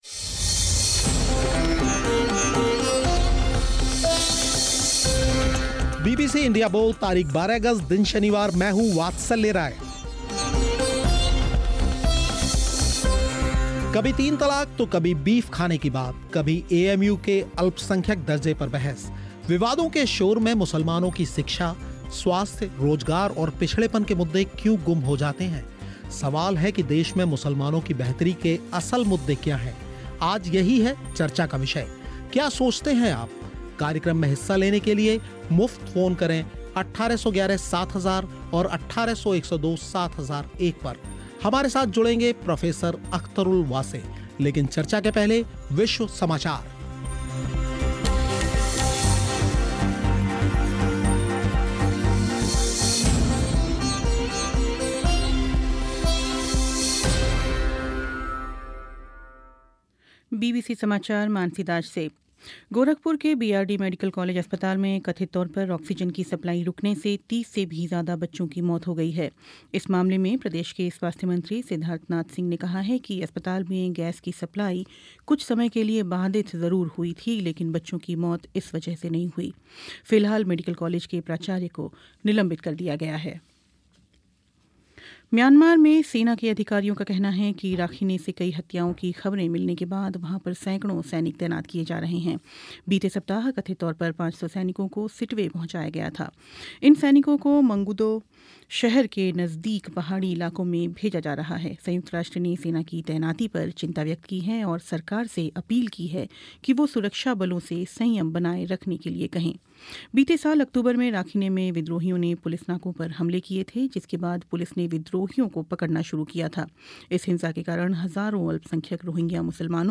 Headliner Embed Embed code See more options Share Facebook X कभी तीन तलाक़ तो कभी बीफ खाने की बात कभी AMU के अल्पसंख्यक दर्जे पर बहस विवादों के इस शोर में मुसलमानों की शिक्षा, स्वास्थ्य, रोजगार और पिछड़ेपन के मुद्दे क्यों गुम हो जाते हैं? सवाल है कि देश में मुसलमानों की बेहतरी के असल मुद्दे क्या हैं? इंडिया बोल में इसी विषय पर हुई चर्चा